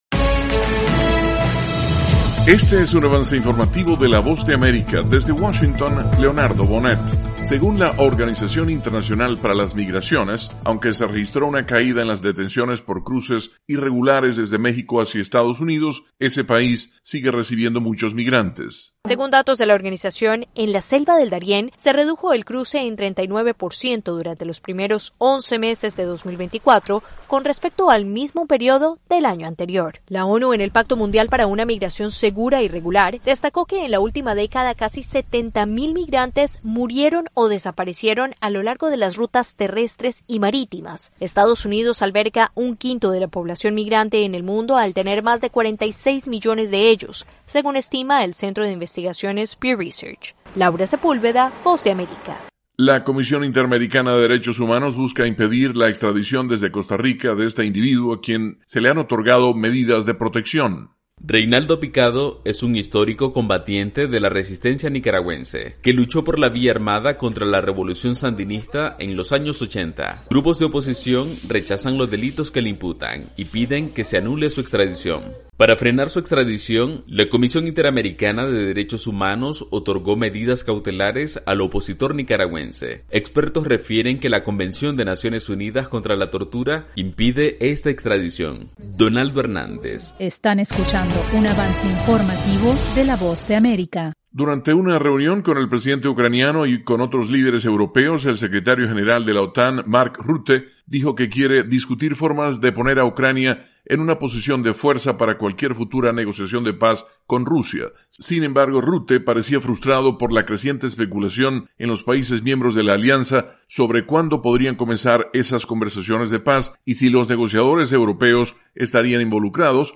El siguiente es un avance informativo presentado por la Voz de América, desde Washington